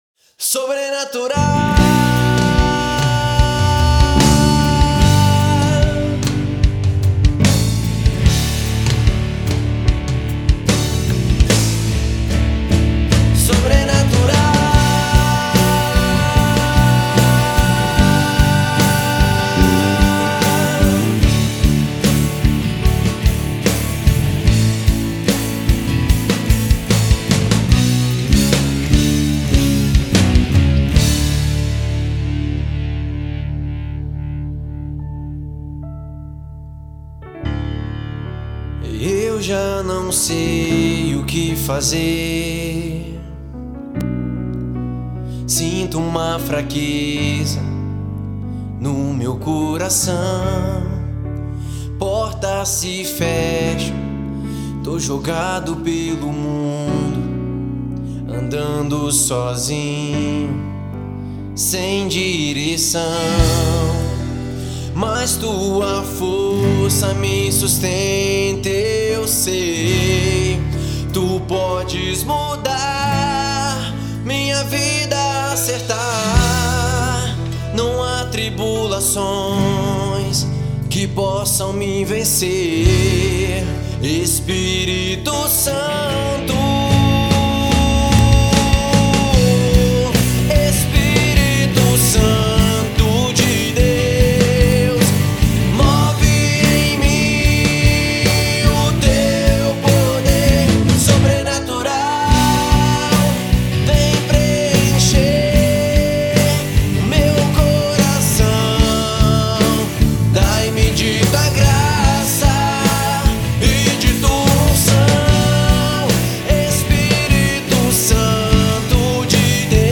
EstiloCatólica